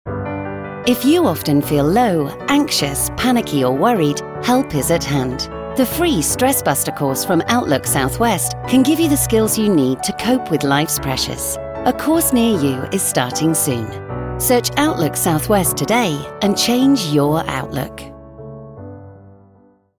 Pirate FM Radio commercial to promote NHS-funded Stress Buster courses throughout Cornwall